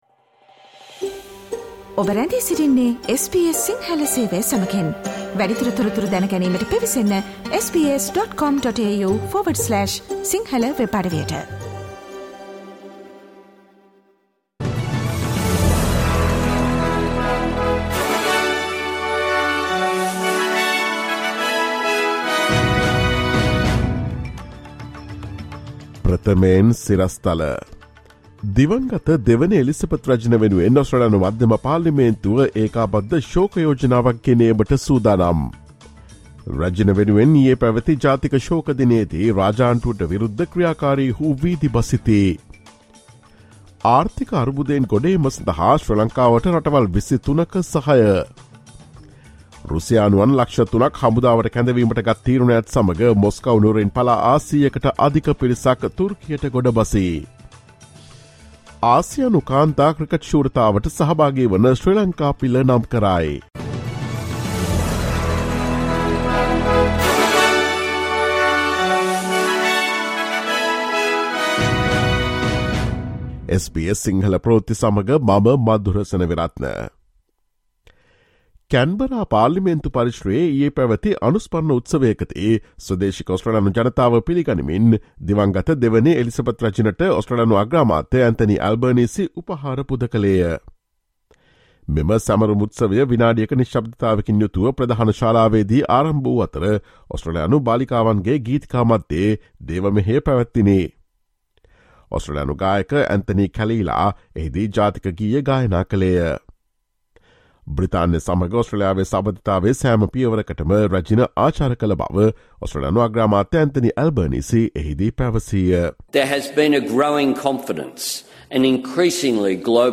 Listen to the latest news from Australia, Sri Lanka, across the globe, and the latest news from the sports world on SBS Sinhala radio news – Friday, 23 September 2022.